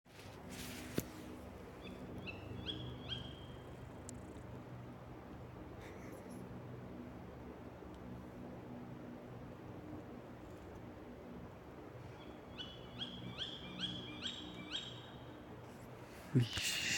Meža pūce, Strix aluco
Administratīvā teritorijaRīga
StatussDzirdēta balss, saucieni